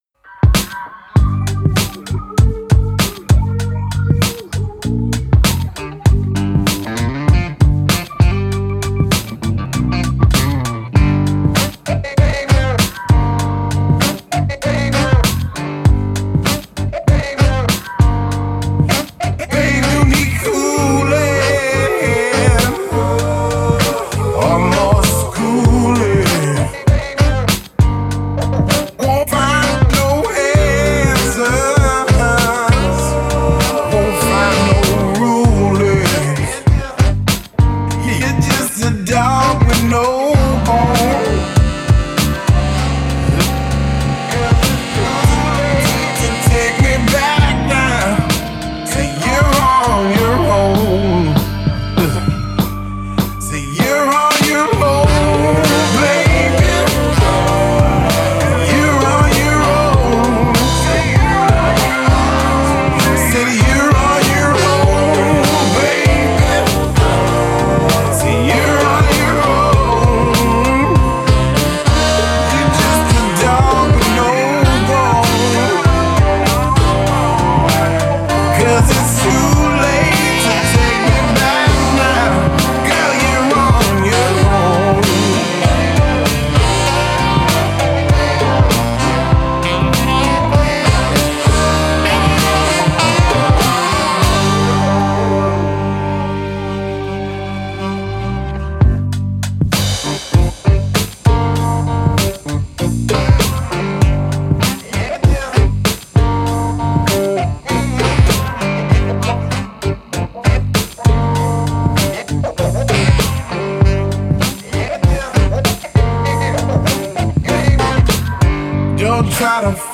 A Remix of an original track
Guitars & Horns all recorded live